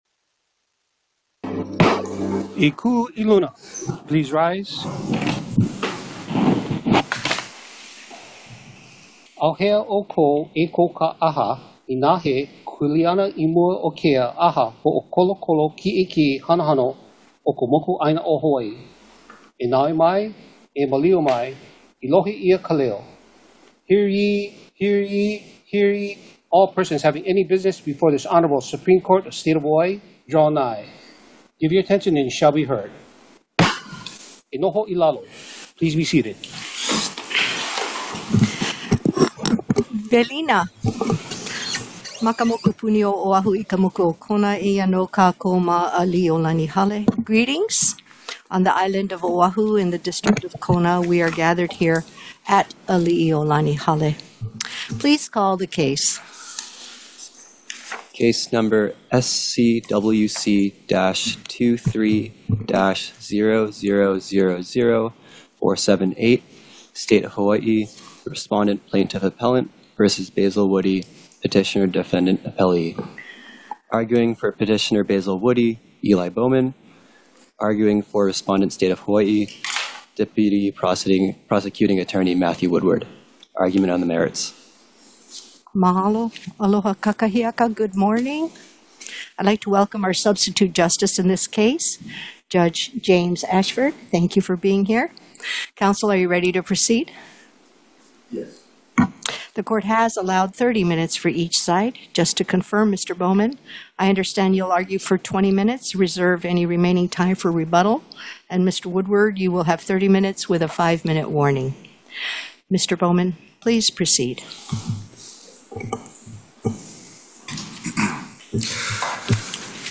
The above-captioned case has been set for oral argument on the merits at: